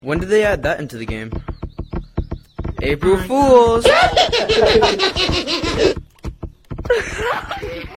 Weird Ahh Goofy Skibidi Laugh Sound Button: Unblocked Meme Soundboard